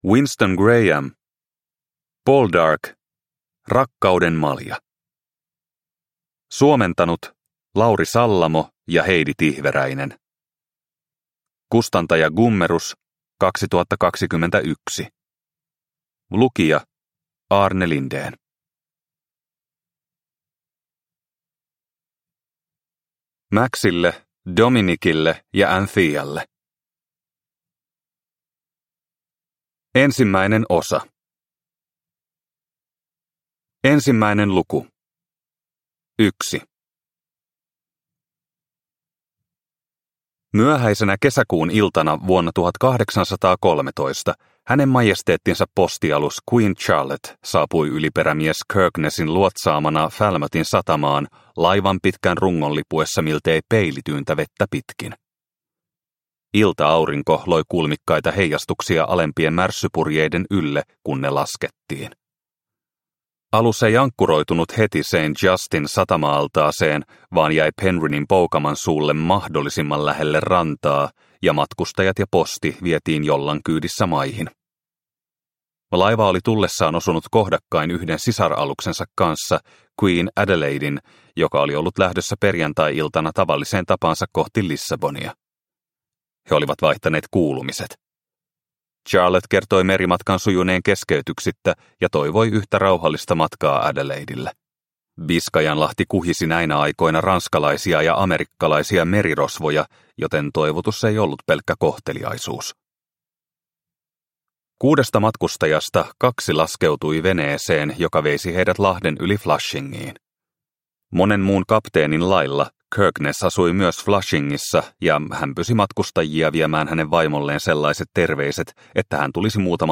Poldark - Rakkauden malja – Ljudbok – Laddas ner